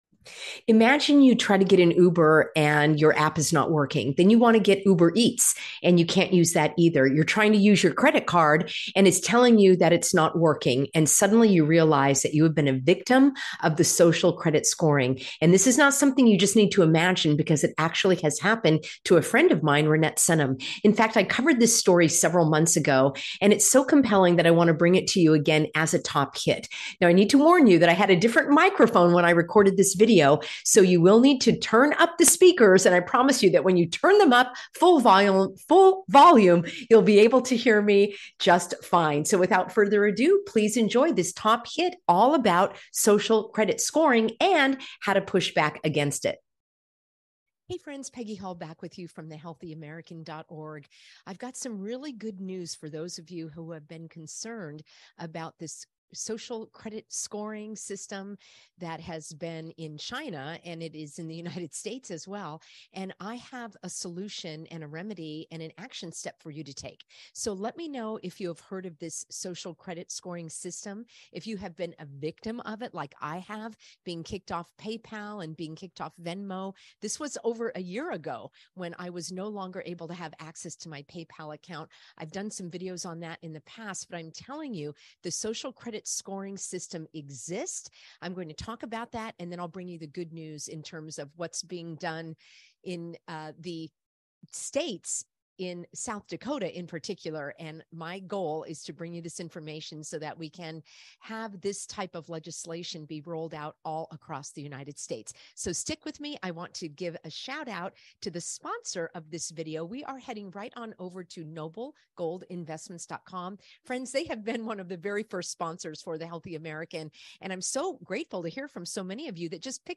Now, I need to warn you that I had a different microphone when I recorded this video. So you will need to turn up the speakers, and I promise you that when you turn them up full volume, you’ll be able to hear me just fine.